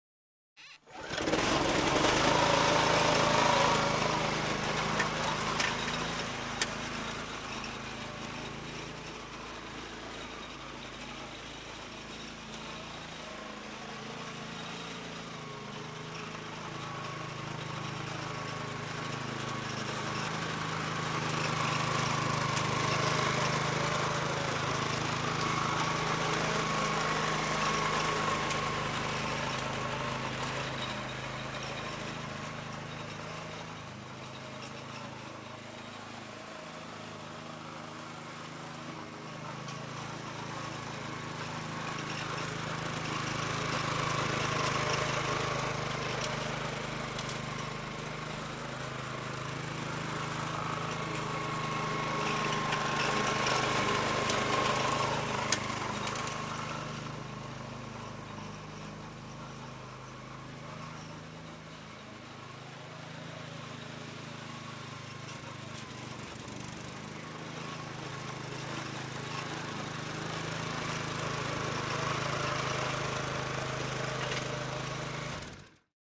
Lawnmowers
in_lawnmower_mowing_02_hpx
Lawn mower starts and mows from left to right then shuts off with engine idles and pulls.